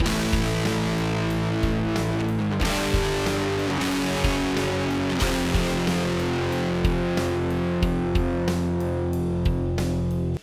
• NORMAL: Klassischer, natürlicher Original-Sound der 80er Jahre
Akkordfolge – Modus: Normal / Gain 100%
Hier wurde der Modus auf Normal gestellt und der Tone etwas angehoben und der Gainschalter voll aufgedreht.